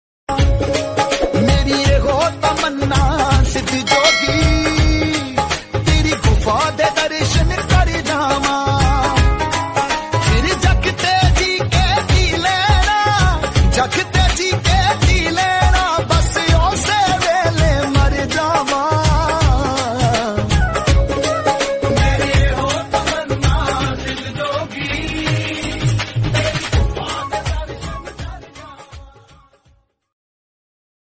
Ringtones Category: Bollywood ringtones
bhajans